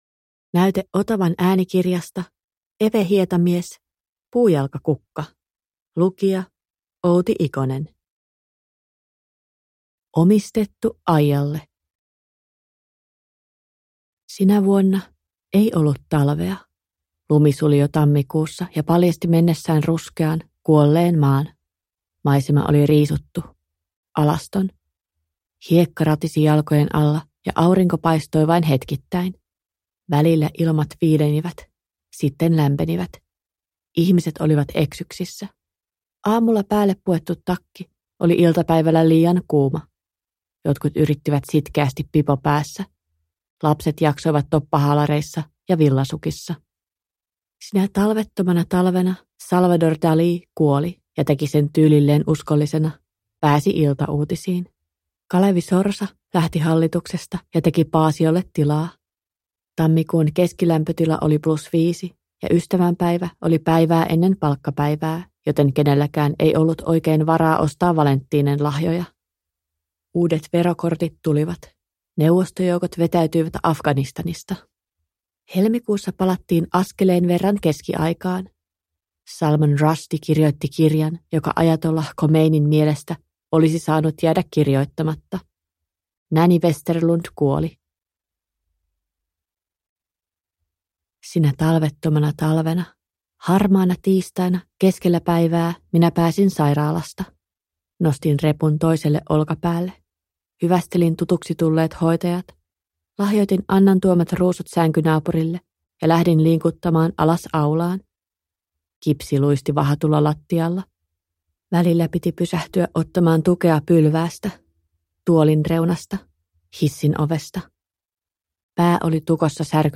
Puujalkakukka – Ljudbok – Laddas ner